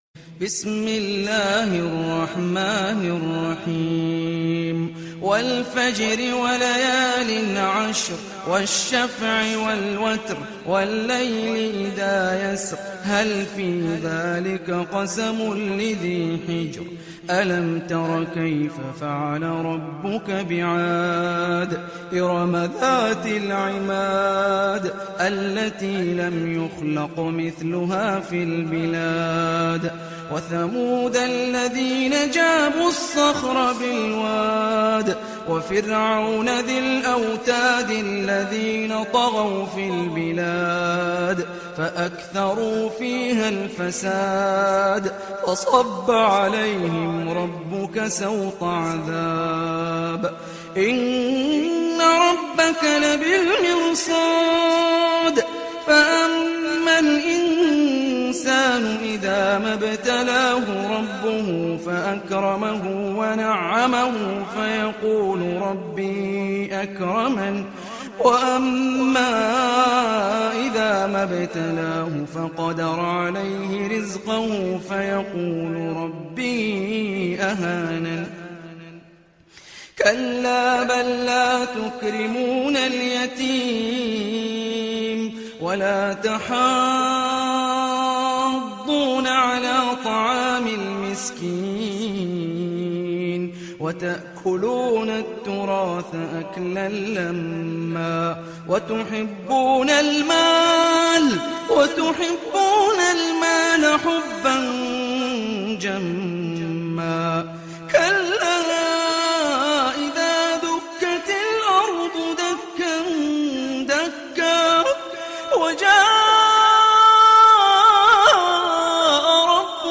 تحميل سورة الفجر mp3 بصوت هاني الرفاعي برواية حفص عن عاصم, تحميل استماع القرآن الكريم على الجوال mp3 كاملا بروابط مباشرة وسريعة